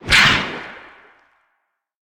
Sfx_creature_seamonkey_flinch_01.ogg